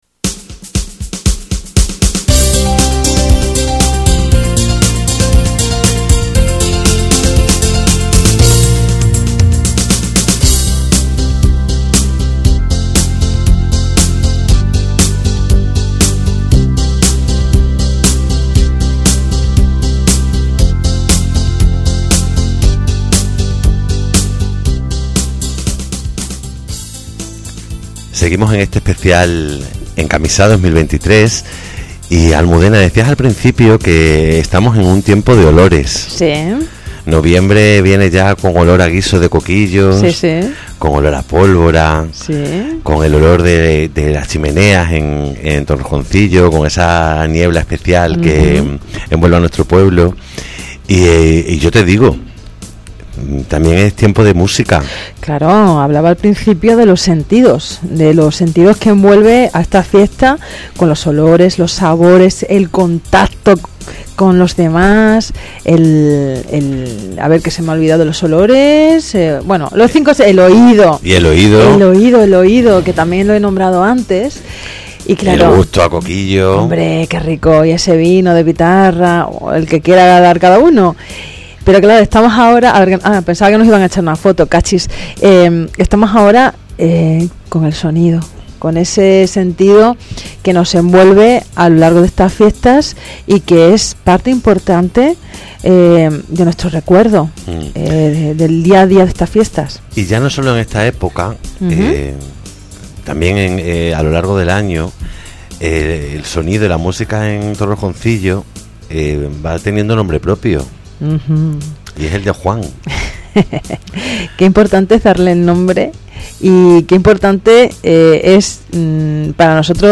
Entrevista
TamborileroAlboraEncamisa2022RadioAlfares.mp3